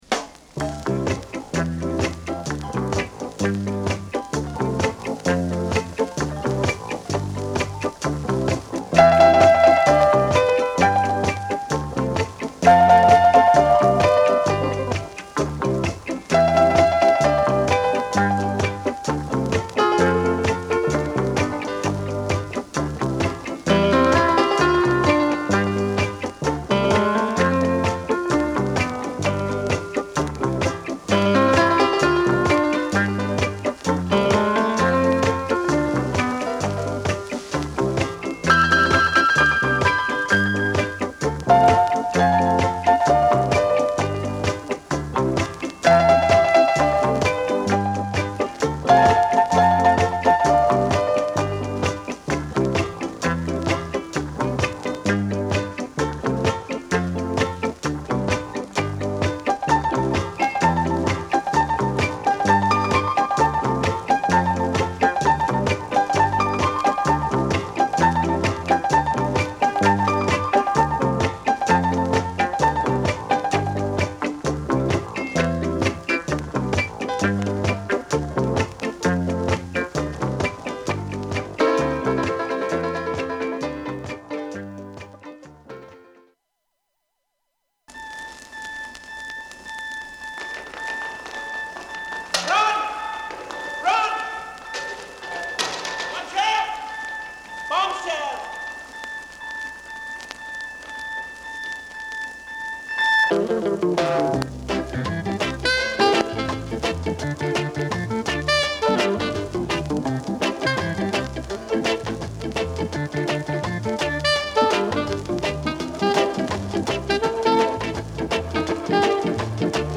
Genre: Early Reggae / Instrumental